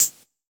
UHH_ElectroHatD_Hit-06.wav